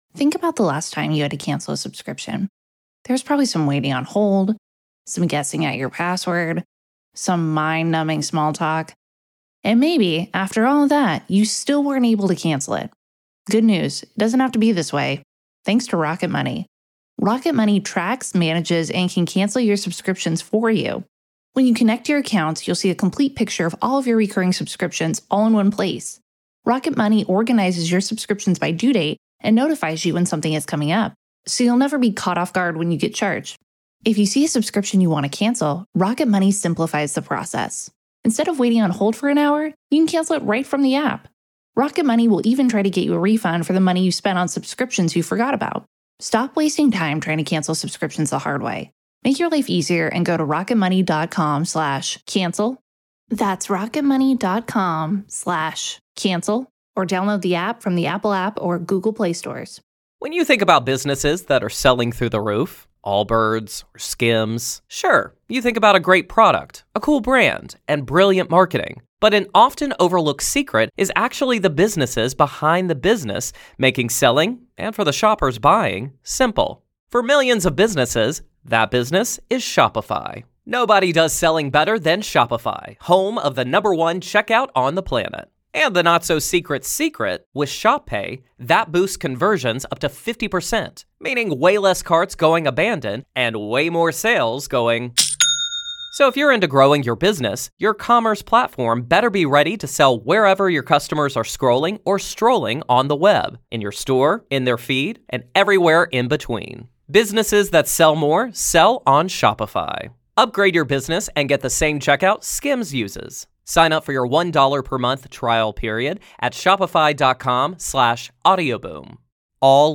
The latest Spanish news headlines in English: October 1st 2025